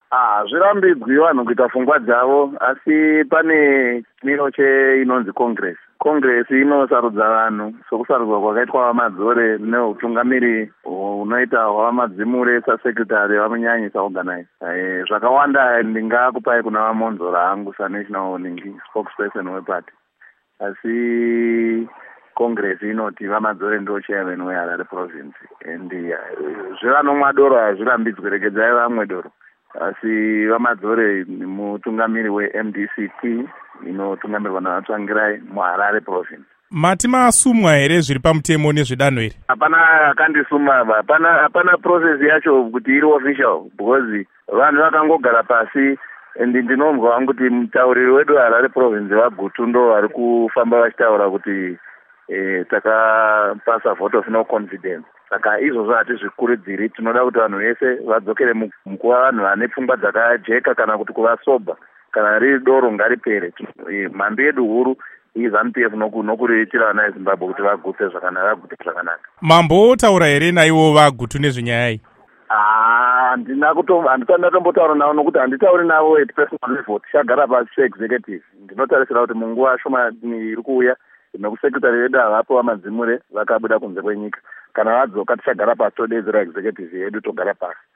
Hurukuro naVaPaul Madzore